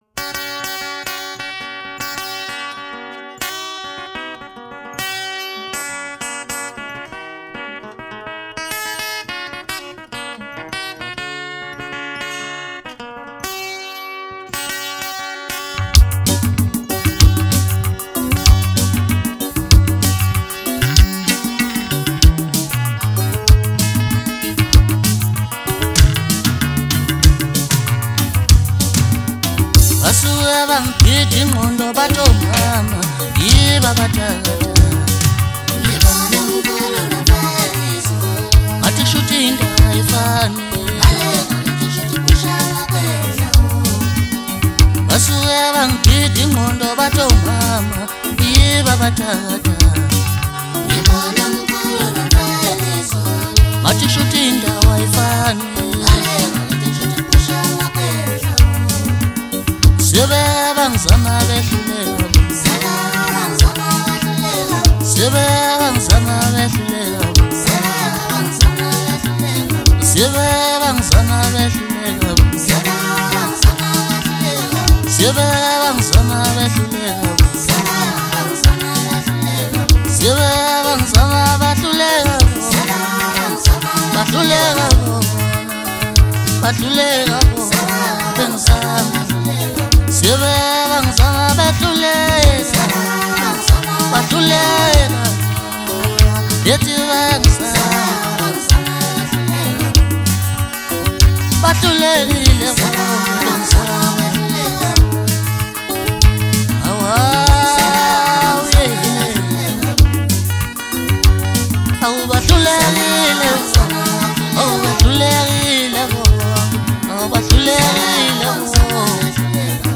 MASKANDI MUSIC